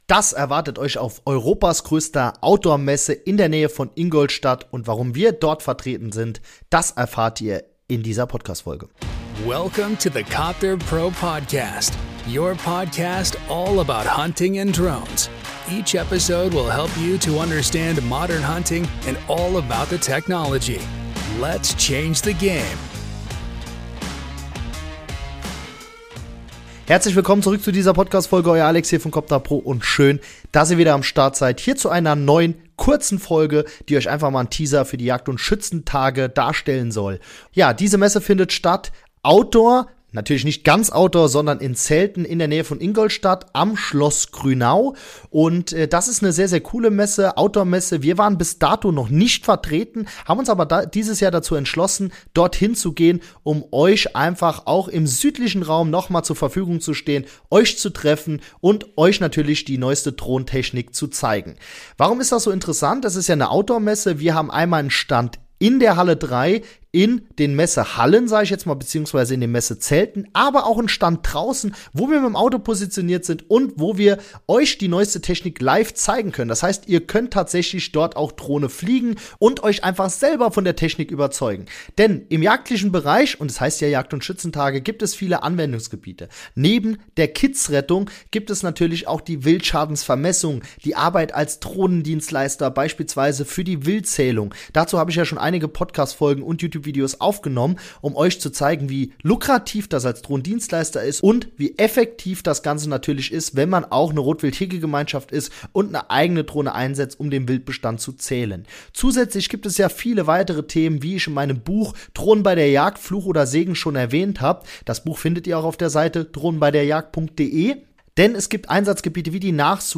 #227 Wärmebilddrohnen TESTEN?! - Wir sind in Grünau auf deutschlands größter Outdoor Messe